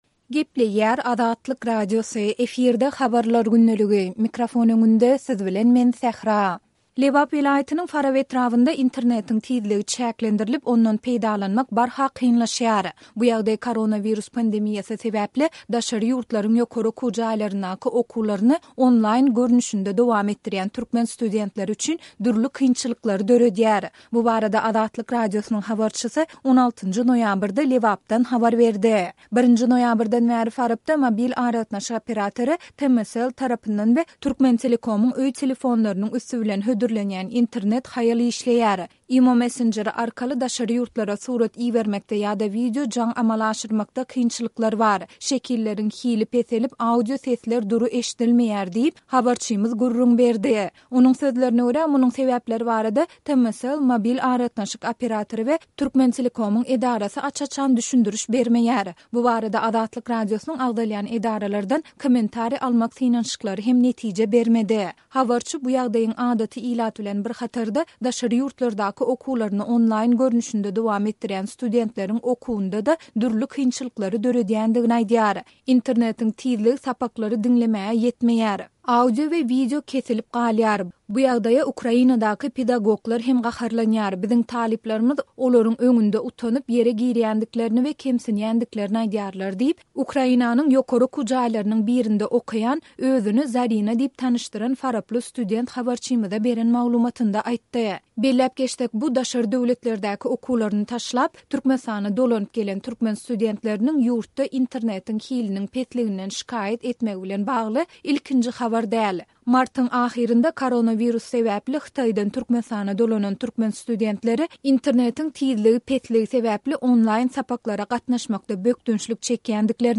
Bu barada Azatlyk Radiosynyň habarçysy 16-njy noýabrda Lebapdan habar berdi.